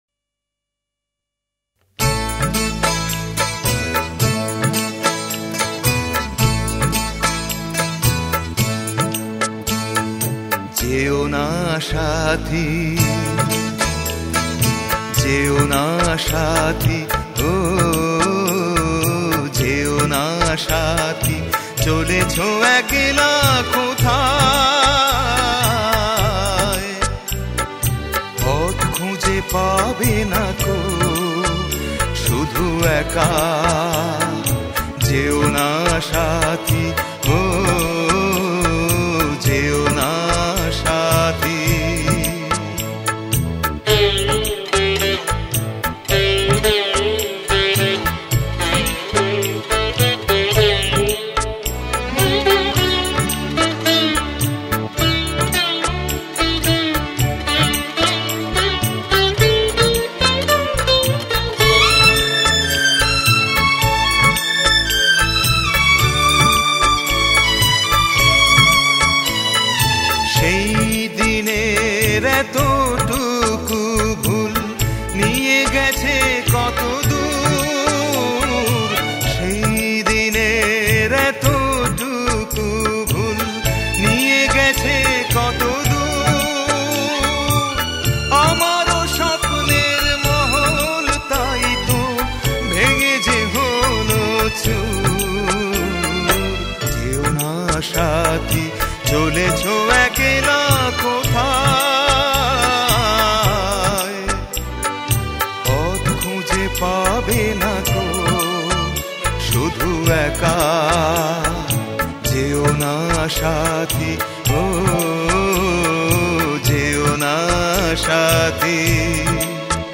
Adhunik Bangla